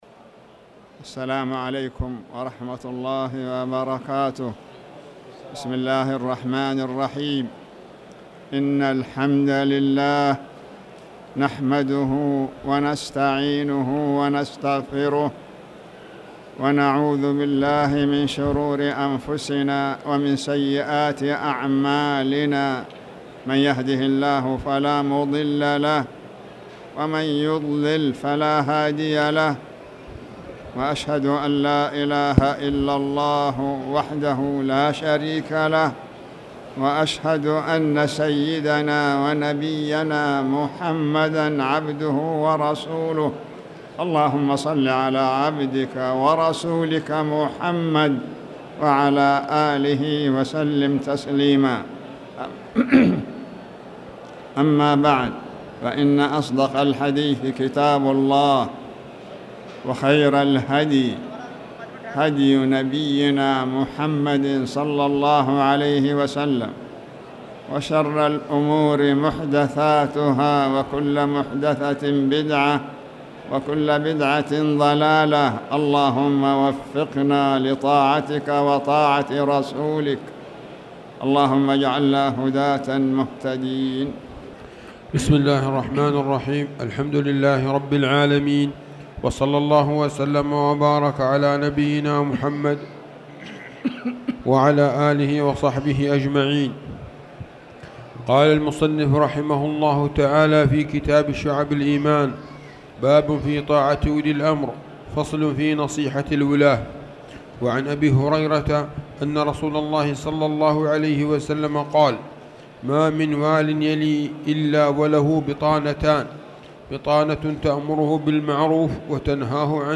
تاريخ النشر ١٠ محرم ١٤٣٨ هـ المكان: المسجد الحرام الشيخ